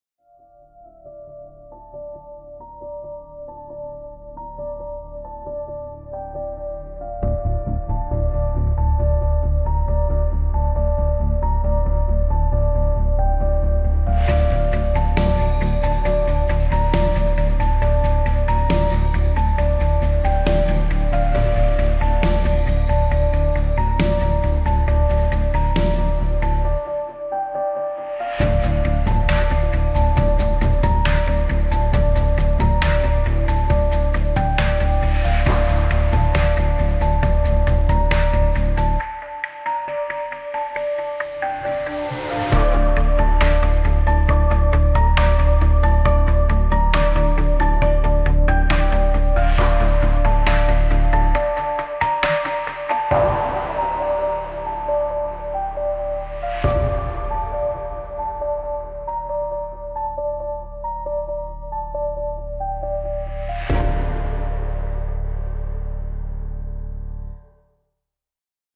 Lost hope - intense 1.08.mp3